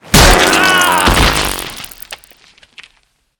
murder.ogg